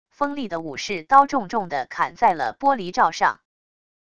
锋利的武士刀重重的砍在了玻璃罩上wav音频